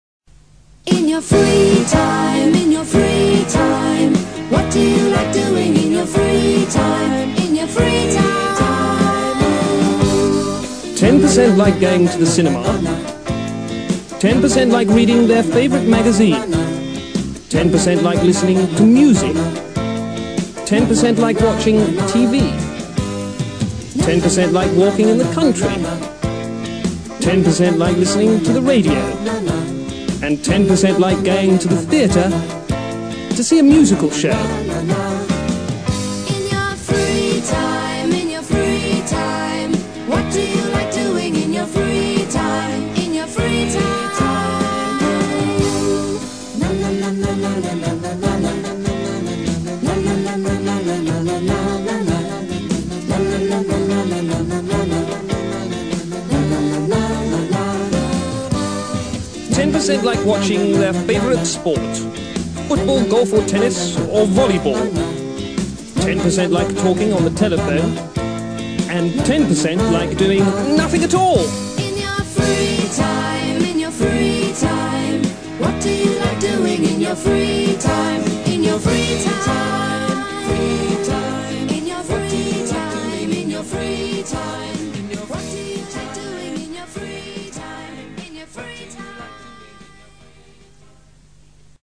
Aprende Gramática Cantando
con esta canción no comercial